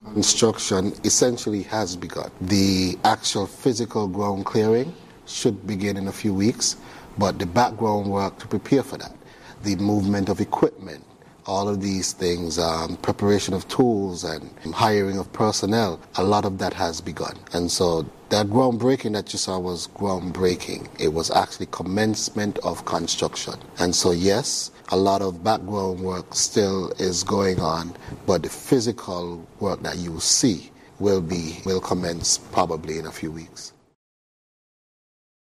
During the first InFocus programme for the year on January 21st, Federal Minister of the Creative Economy, the Hon. Samal Duggins shared this information while discussing the Creative Centre: